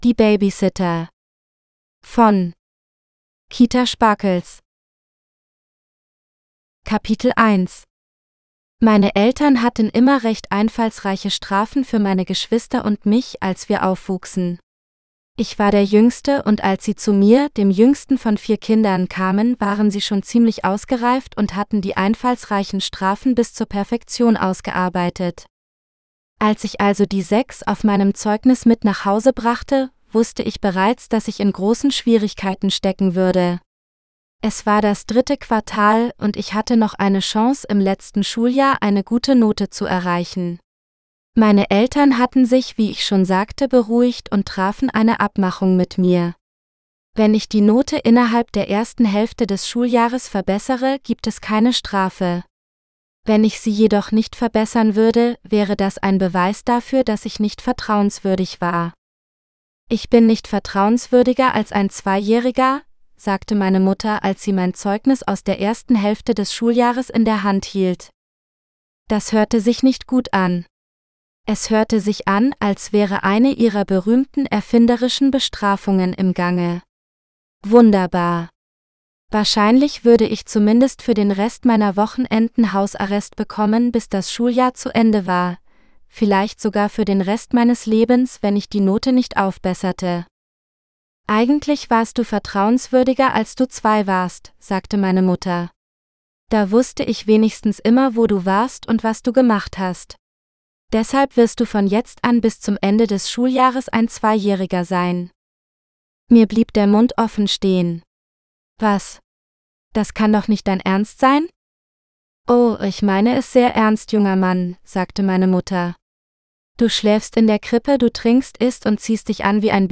Die Babysitter GERMAN (AUDIOBOOK – female): $US5.75